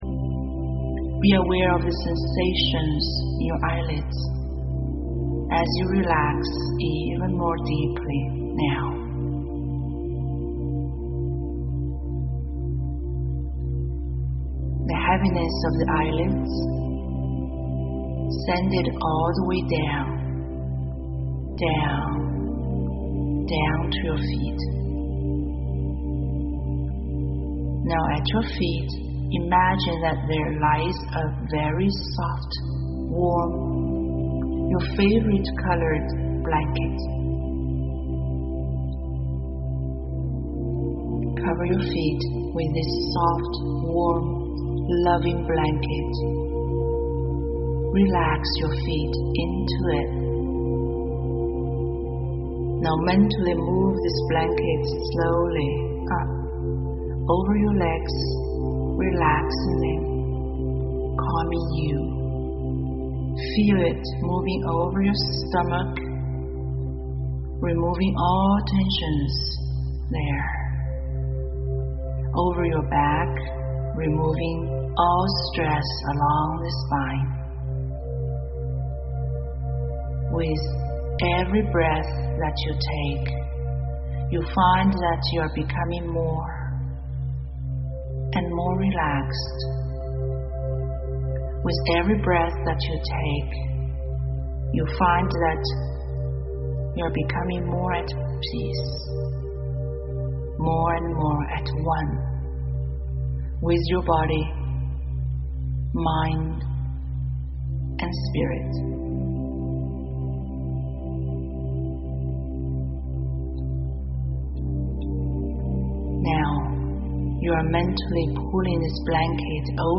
Relax and Sleep Better – Hypnosis for Insomnia
This high-quality recording can be listened to at any time of the day but is best at bedtime.